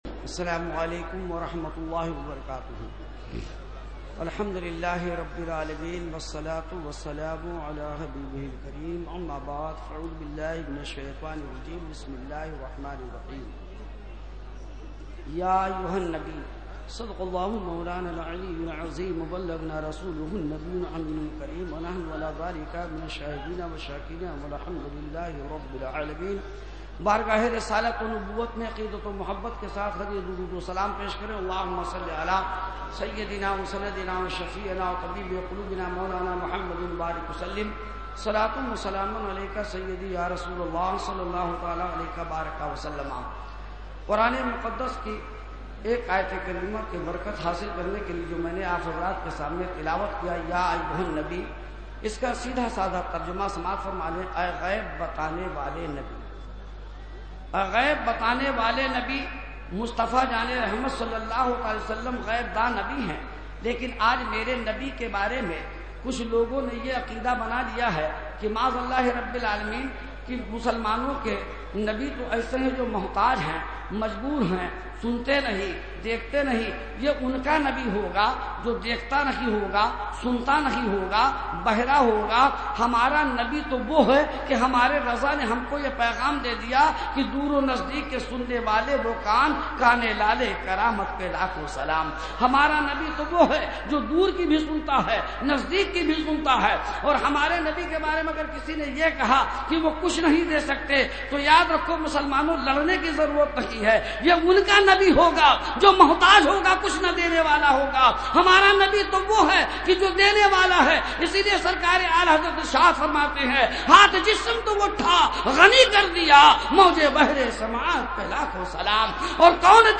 013-Speech.mp3